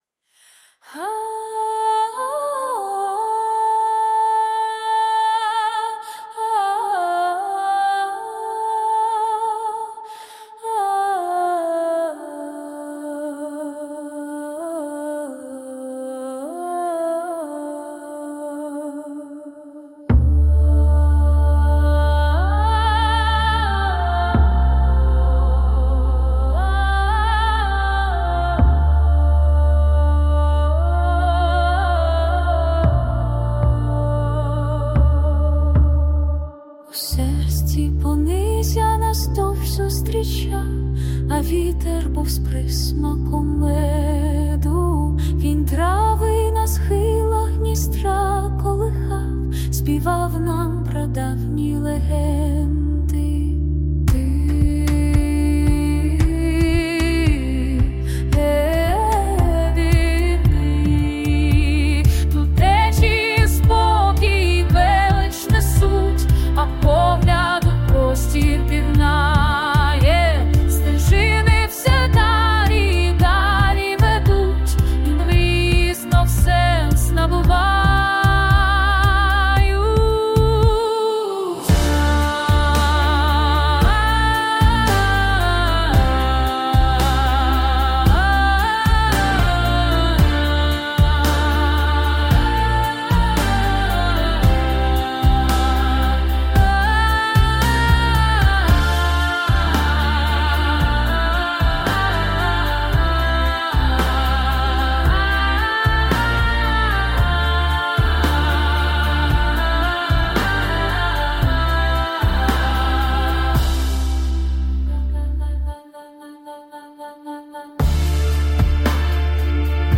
Пониззя (етно, уютний вокал, хор, струнні).mp3
Текст автора, виконання штучне
ТИП: Пісня
СТИЛЬОВІ ЖАНРИ: Ліричний